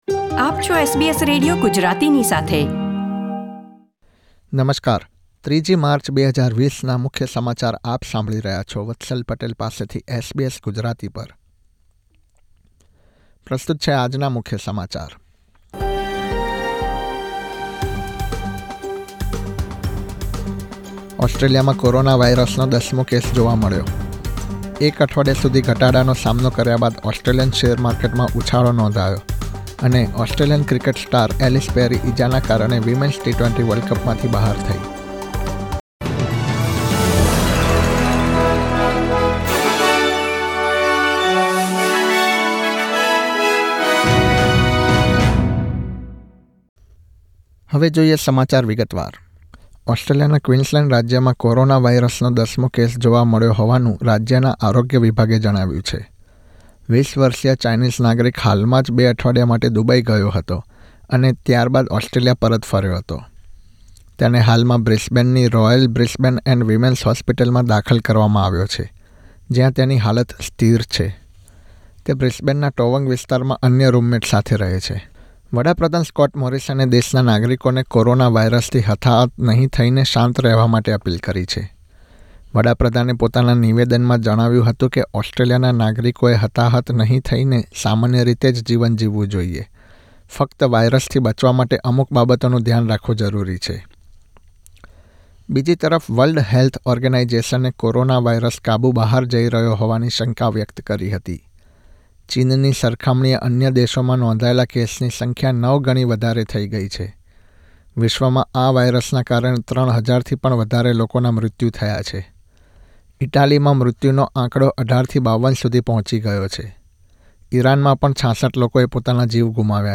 SBS Gujarati News Bulletin 3 March 2020